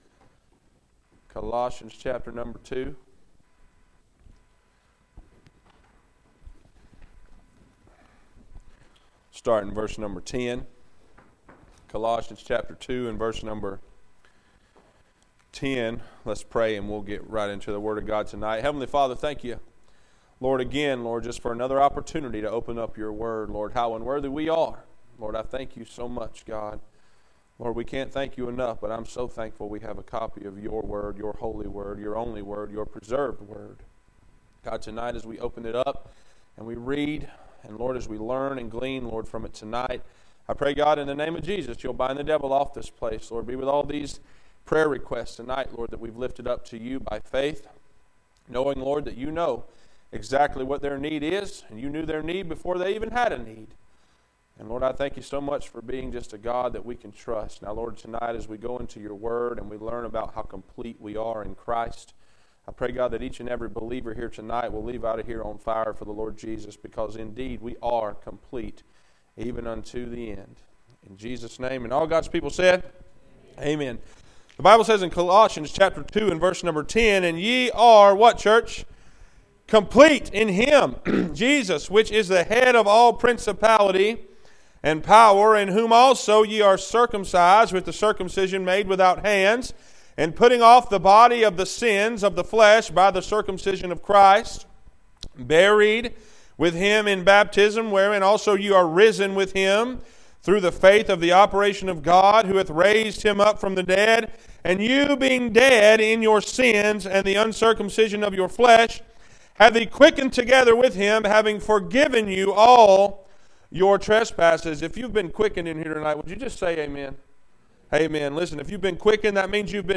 Passage: Col 2:10-15, Eph 6:12< Phil 1:4-7, James 1:1-4, Jn 14:25-275 Service Type: Wednesday Night